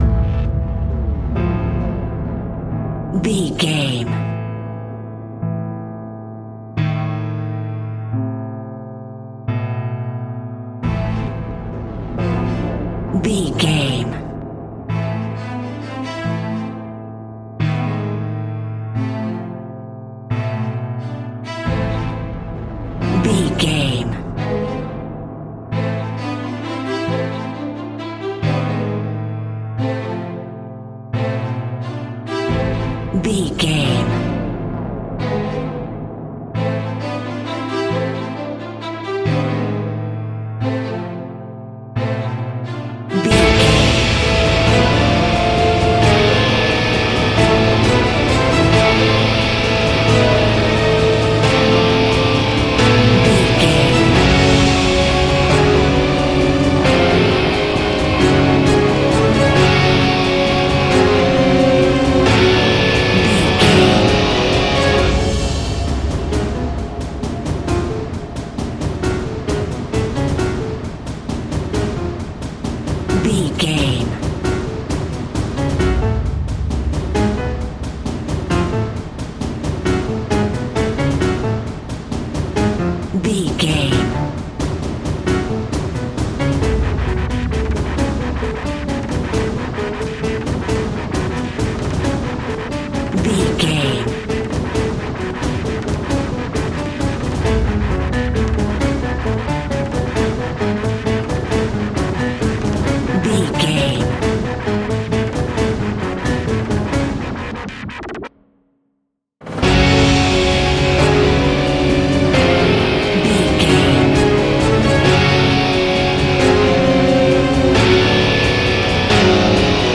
In-crescendo
Thriller
Aeolian/Minor
scary
ominous
suspense
haunting
eerie
strings
piano
percussion
brass
electric guitar
synth
pads